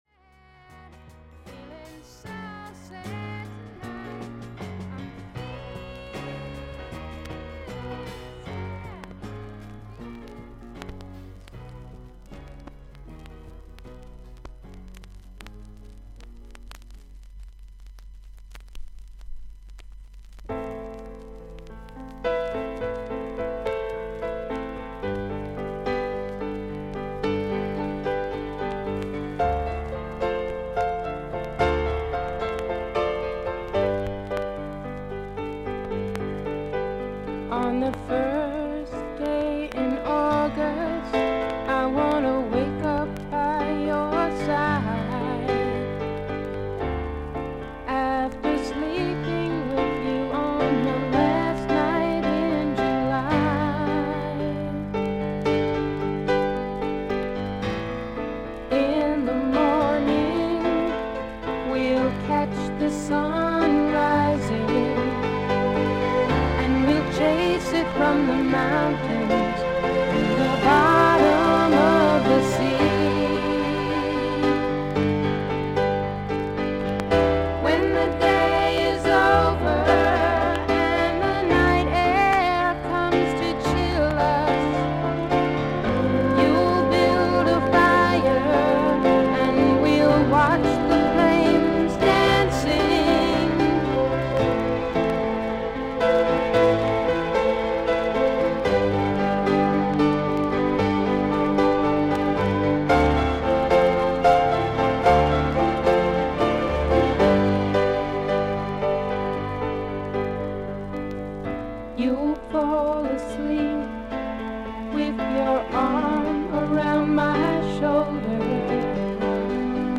ほかはVG+〜VG++:少々軽いパチノイズの箇所あり。少々サーフィス・ノイズあり。クリアな音です。
女性シンガー/ソングライター。